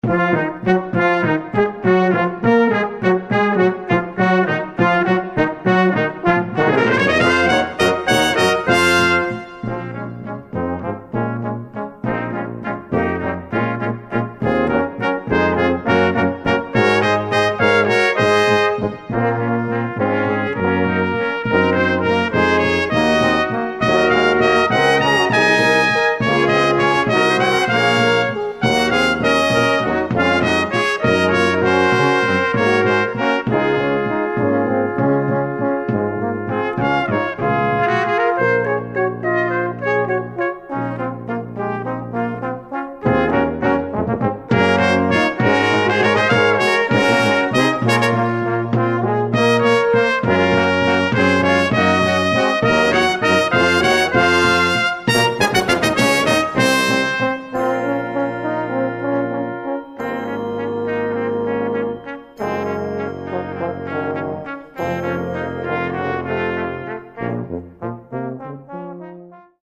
Kammermusik
Brass Quintets